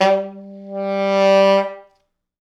Index of /90_sSampleCDs/East West - Quantum Leap Horns Sax/Quantum Leap Horns Sax/T Sax fts